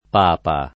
[ p # pa ]